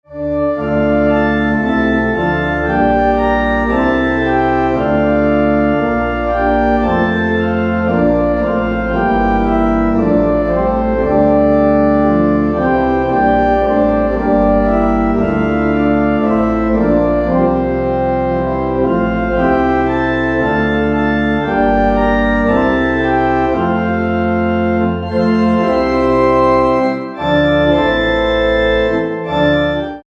Organ
Em